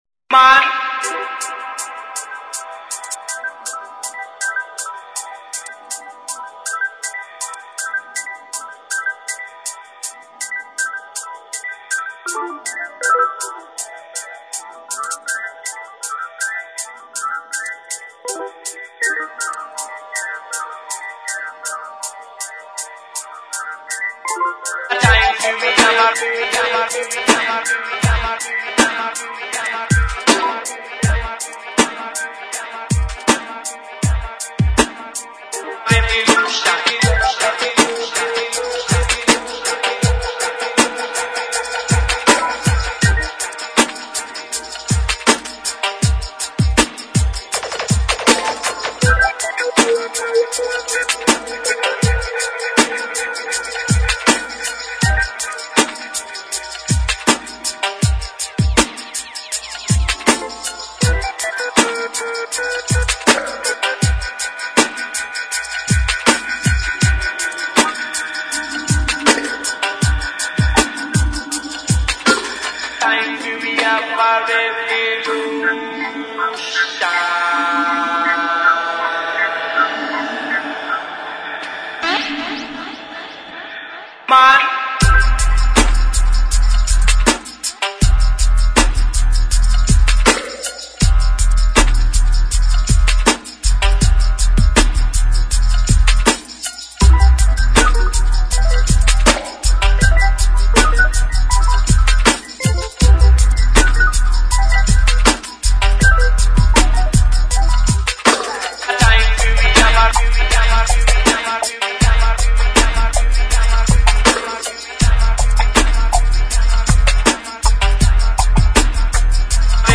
[ DUB / DUBSTEP ]